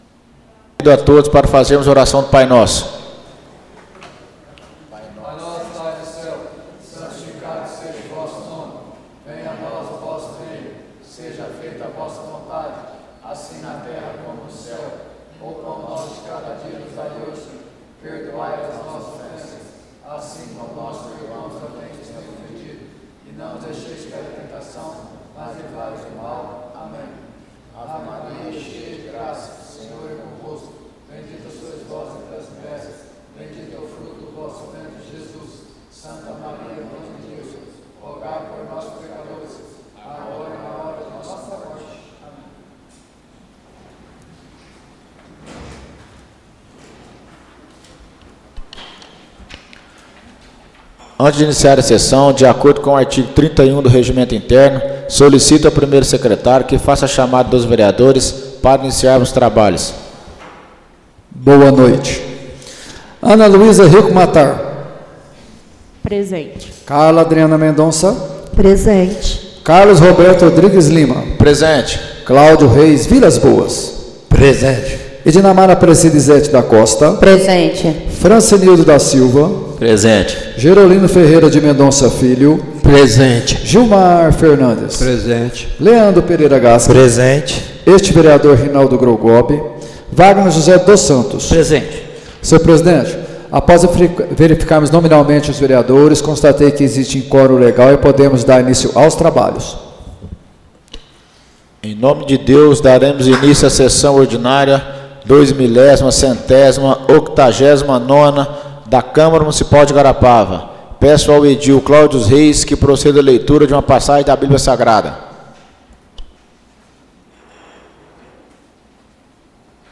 Áudio da Sessão Ordinária de 29/10/2024